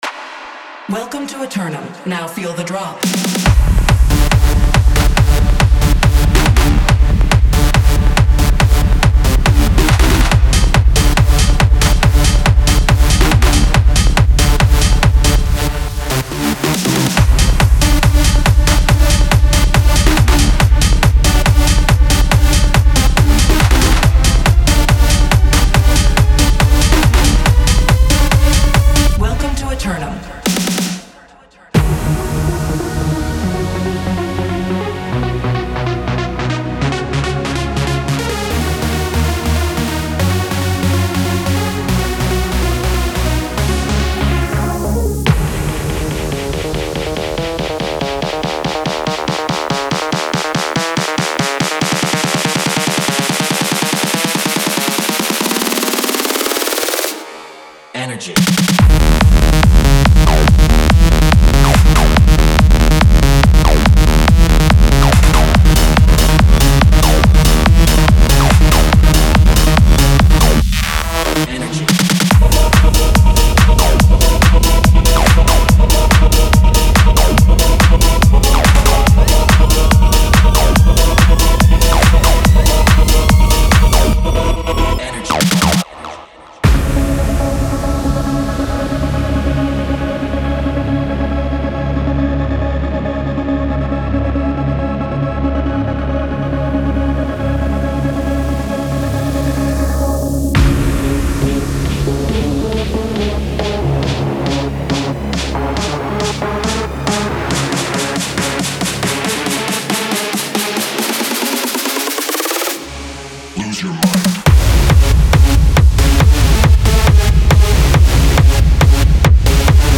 Genre:Techno
デモサウンドはコチラ↓
40 Full Drum Loops, Kick&Snare Loops, Top Loops